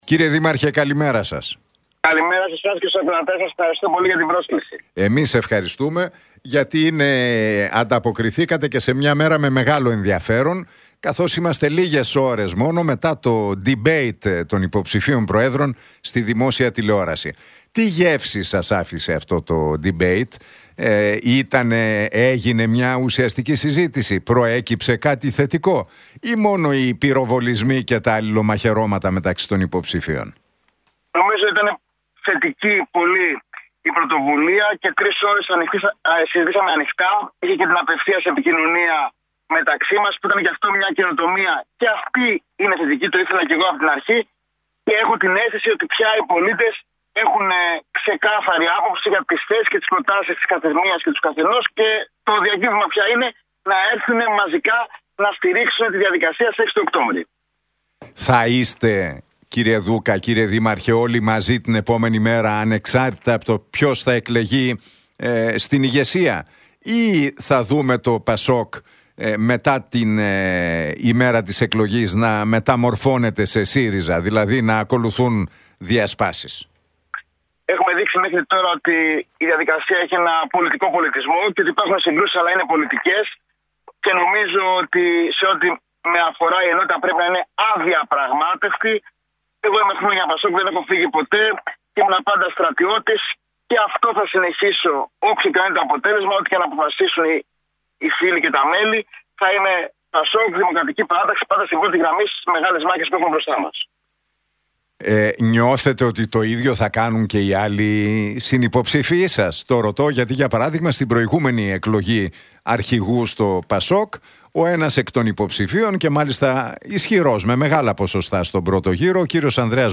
Για το ντιμπέιτ των υποψηφίων προέδρων του ΠΑΣΟΚ, τις εντυπώσεις του, την κριτική που δέχεται, την αυτοδιοίκηση και τις εσωκομματικές εκλογές μίλησε ο δήμαρχος Αθηναίων, Χάρης Δούκας στον Realfm 97,8 και τον Νίκο Χατζηνικολάου.